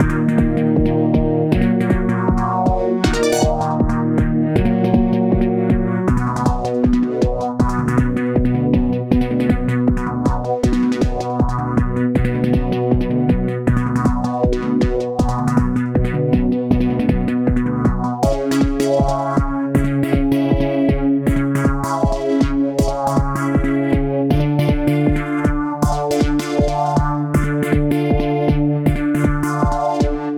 シンプルなシンセサイザーで仕上げたサイバー風BGMです！